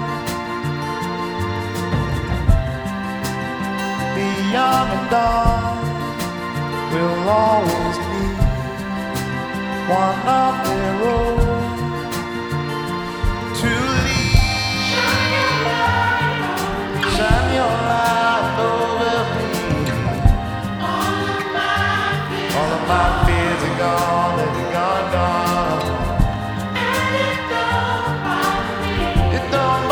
• Soul